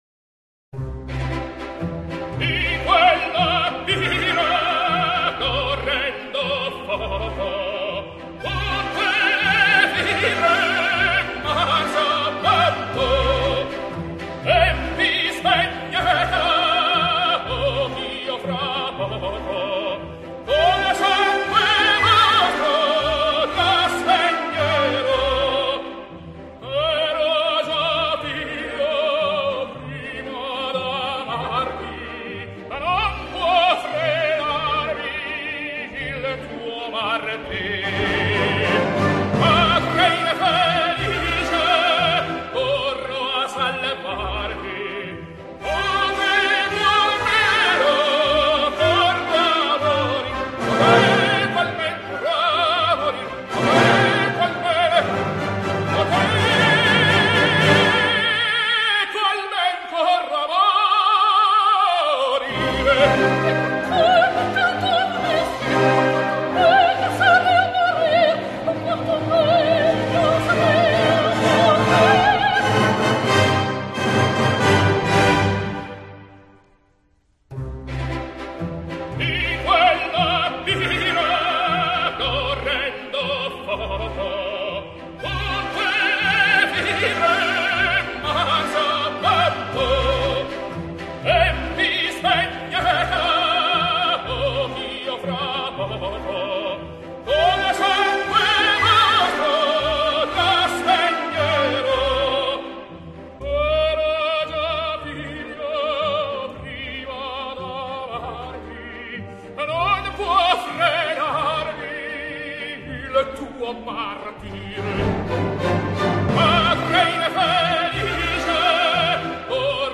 Voix d'hommes - Coups de coeur
Ténor - Luciano Pavarotti
0413_Di_quelle_pira_Rigoletto_Giuseppe_Verdi_Tenor_Luciano_Pavarotti.mp3